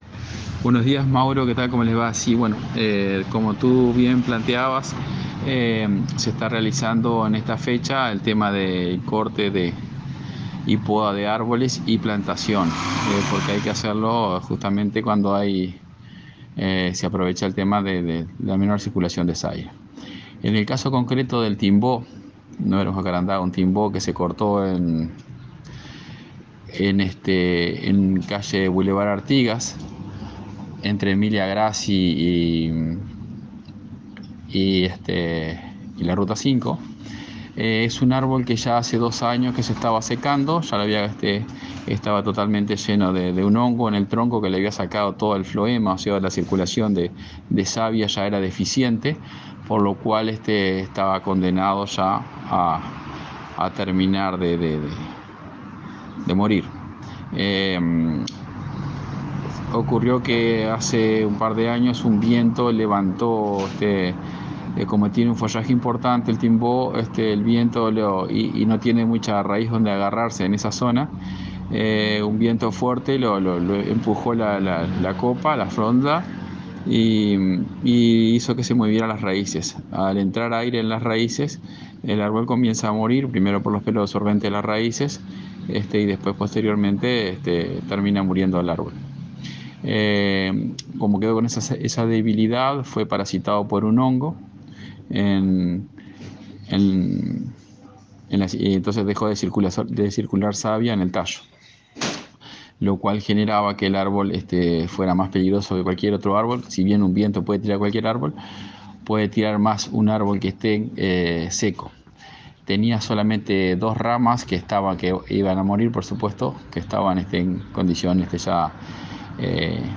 Consultamos al Director General de Servicios Ing. Agr. Wilson Malceñido sobre la eliminación de árbol Timbó ubicado en Br. Artigas entre Ruta 5 y Emilia Grassi.